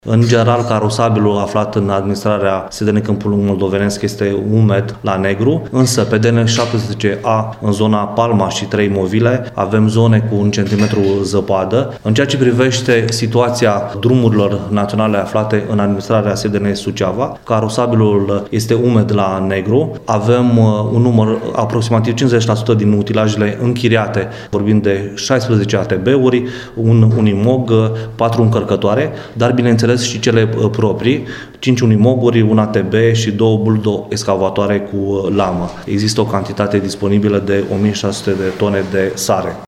Prefectul ALEXANDRU MOLDOVAN a declarat că drumarii sunt pregătiți să facă față ninsorilor.